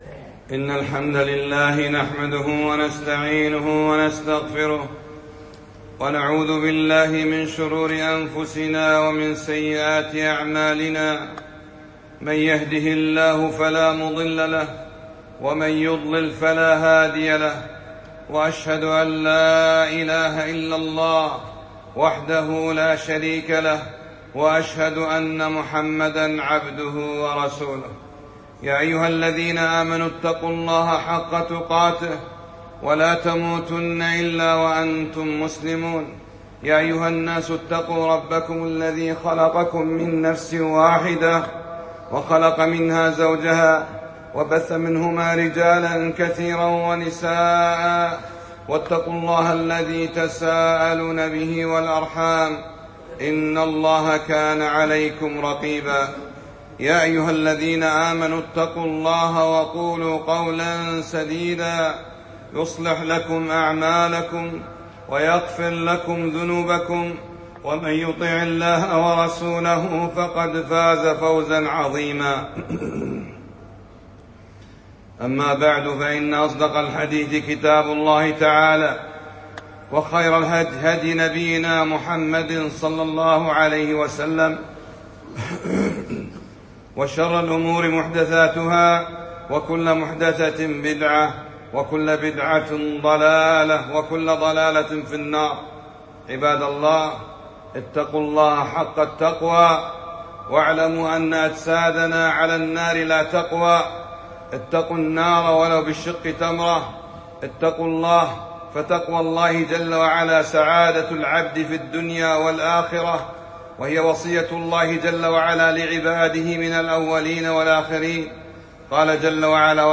خطبة - (يغفر للشهيد كل شيء إلا الدَّين)